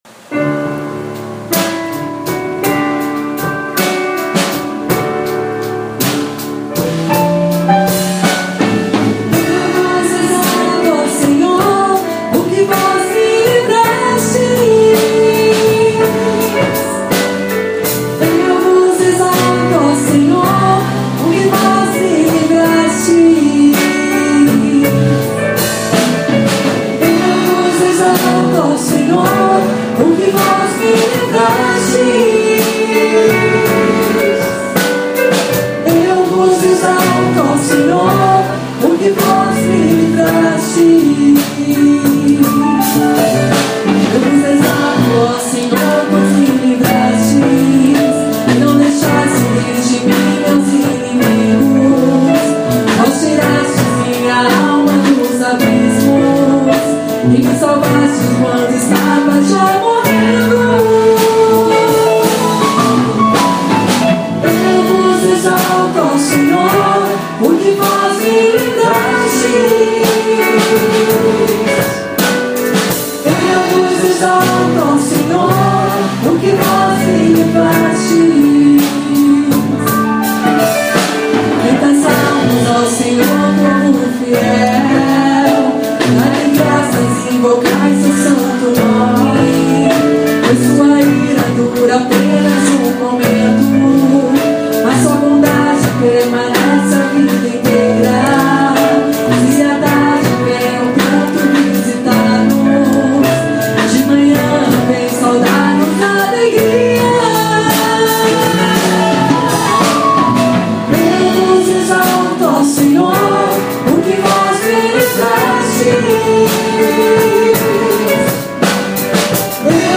salmo 29-30-Gravacao ensaio.mp3